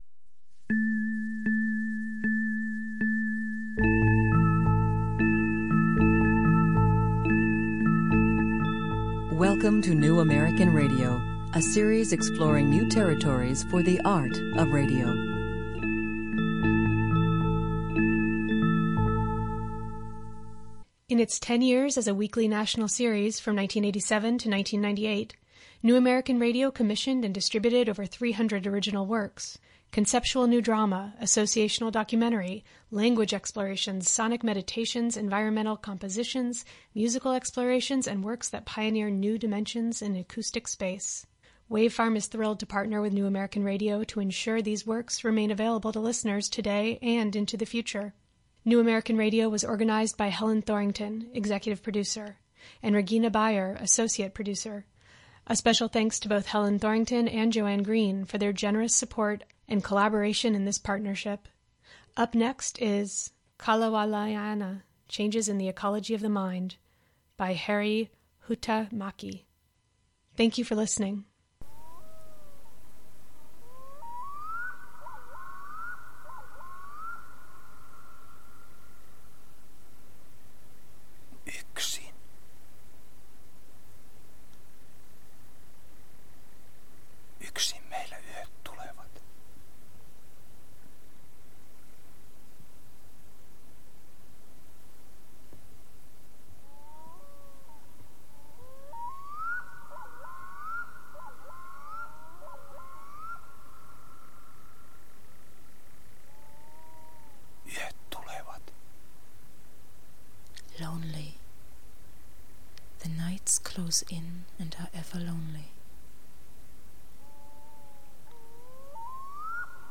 Bear Island, a remote wilderness area of Finland served as the primary recording location for this remarkable work that reverberates with the sounds of nature and ancient musical instruments. Additional recordings were made in the streets of Helsinki
improvised on bass and saxophone
Produced for the Finnish Broadcasting Company (YLE).